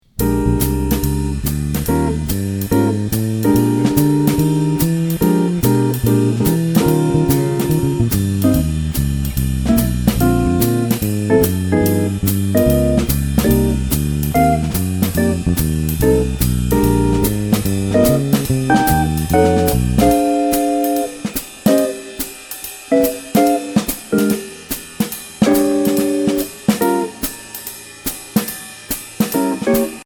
Voicing: Bass Method